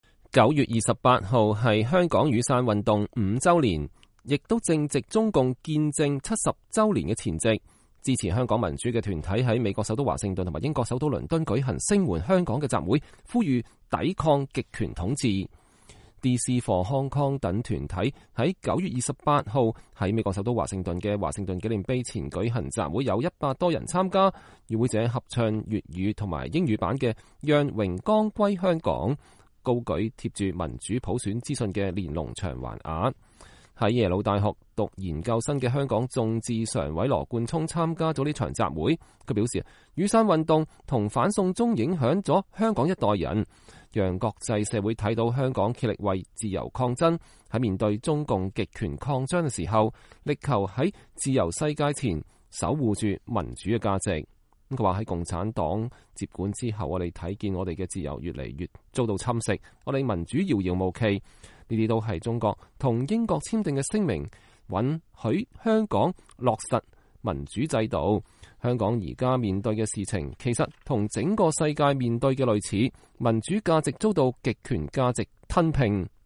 美國首都華盛頓聲援香港集會
DC4HK等團體9月28日在美國首都華盛頓的華盛頓紀念碑前舉行集會，有一百多人參加。與會者合唱粵語和英語版“讓榮光歸香港”，高舉貼著民主普選信息的連農牆橫幅。
英國首都倫敦聲援香港集會